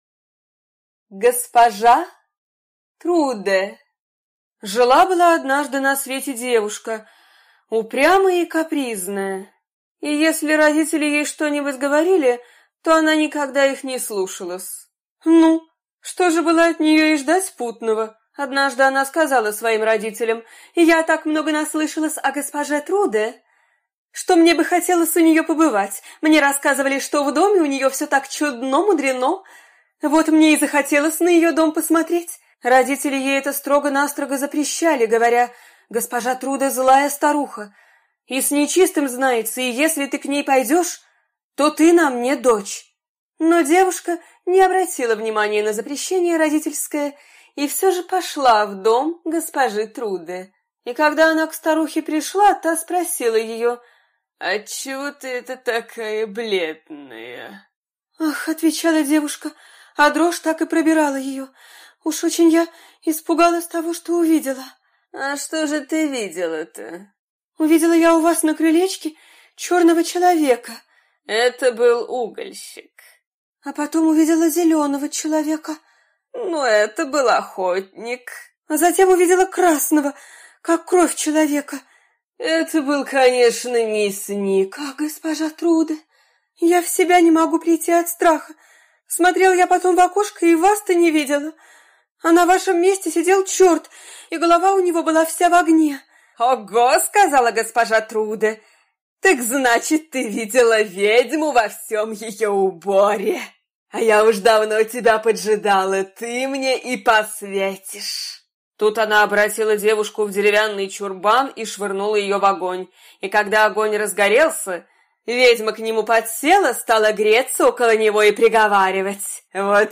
Госпожа Труде - аудиосказка Братьев Гримм.